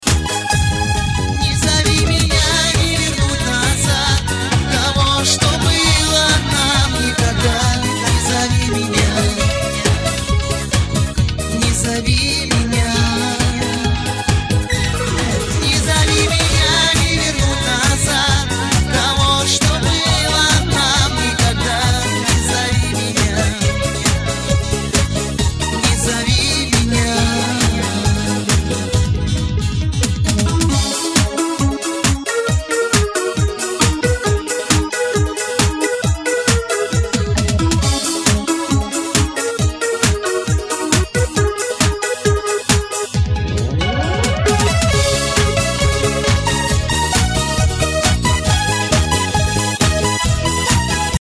• Качество: 320, Stereo
мужской вокал
грустные
русский шансон